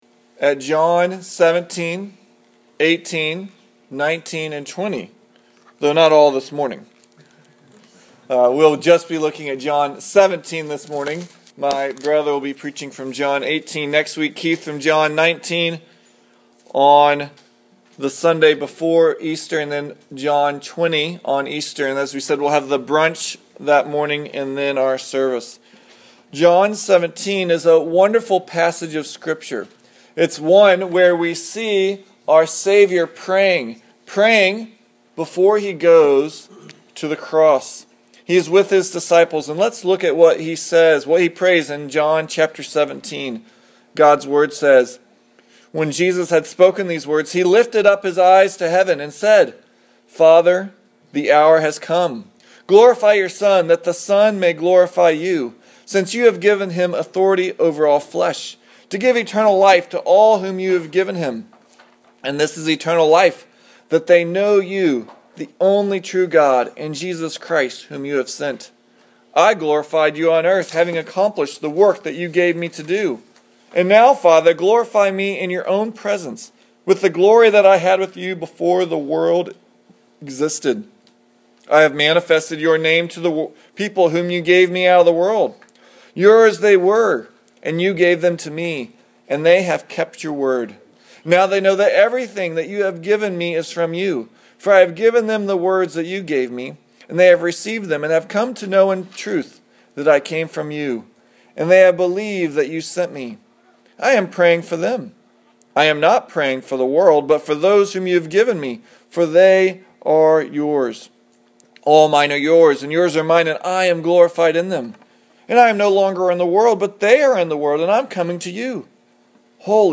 Passage: John 17:1-26 Service Type: Sunday Morning